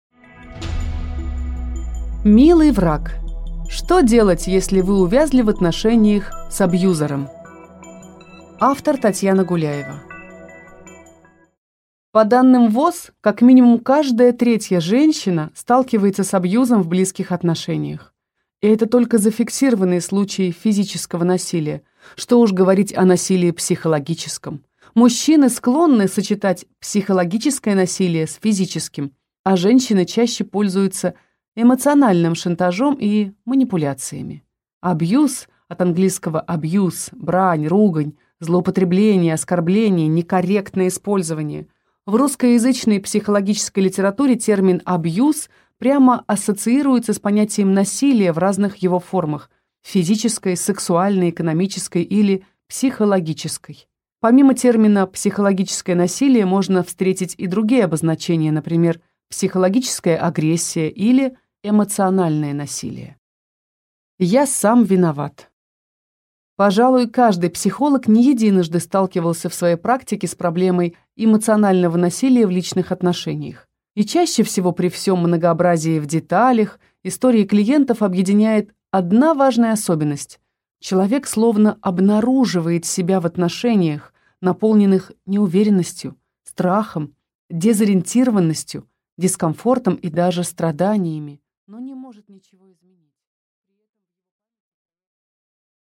Аудиокнига Милый враг | Библиотека аудиокниг
Прослушать и бесплатно скачать фрагмент аудиокниги